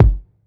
• Low Tone Kickdrum Sample G Key 740.wav
Royality free steel kick drum sample tuned to the G note. Loudest frequency: 178Hz
low-tone-kickdrum-sample-g-key-740-70Q.wav